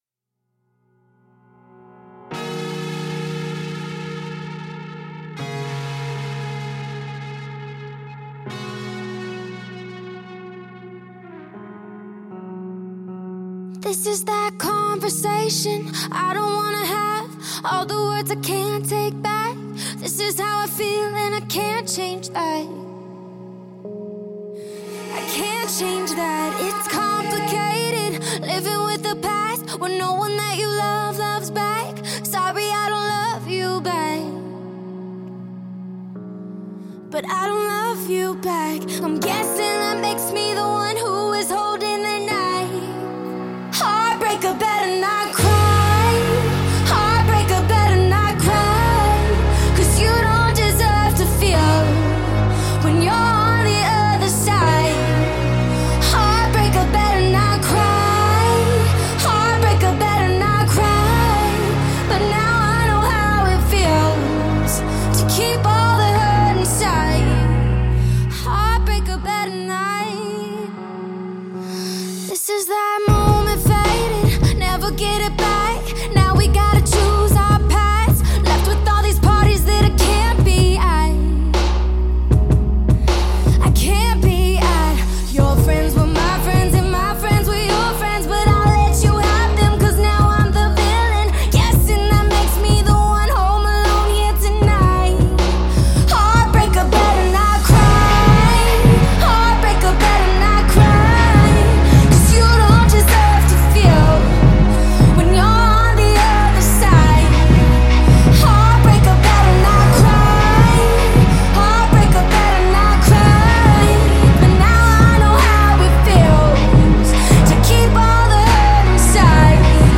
# Pop # Dance